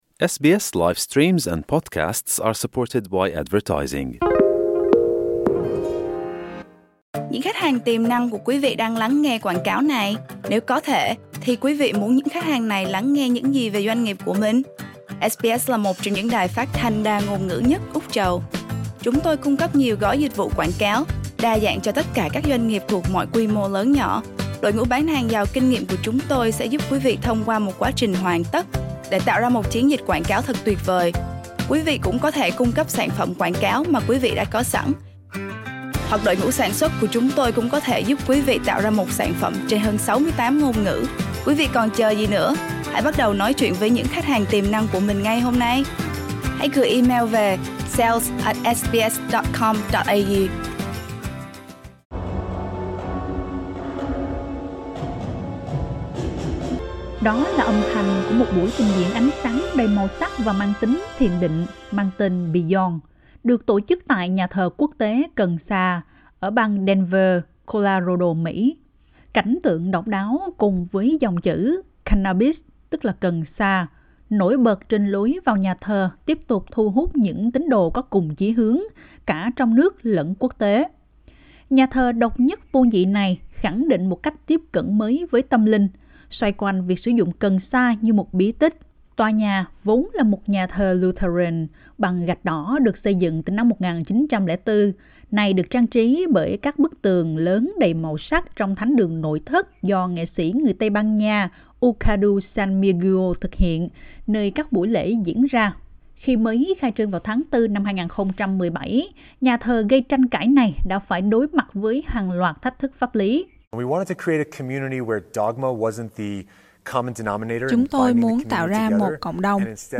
Đó là âm thanh của một buổi trình diễn ánh sáng đầy màu sắc và mang tính thiền định mang tên Beyond, được tổ chức tại Nhà thờ Quốc tế Cần sa ở bang Denver, Colorado, Mỹ.